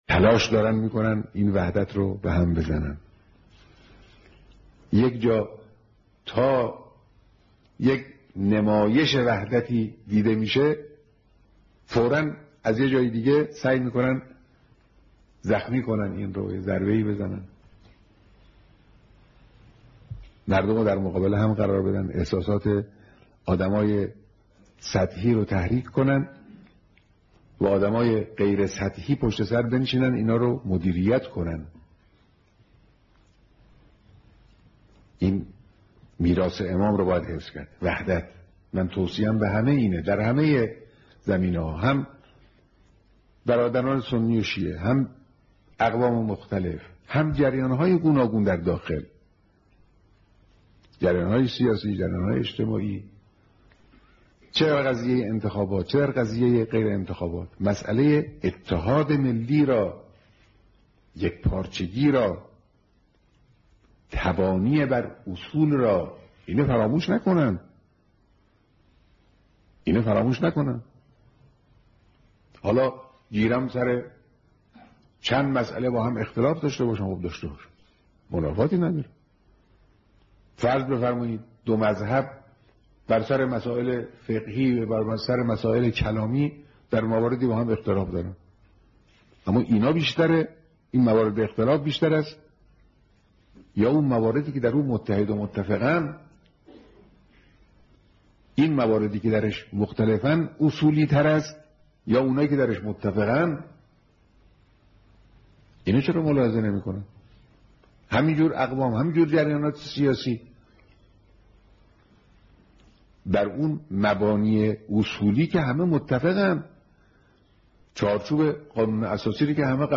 ہفتۂ مقدس دفاع کے موقع پر مقدس دفاع کے کمانڈروں اور سینیئر سپاہیوں سے ملاقات میں تقریر